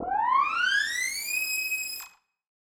Index of /musicradar/future-rave-samples/Siren-Horn Type Hits/Ramp Up
FR_SirHornE[up]-E.wav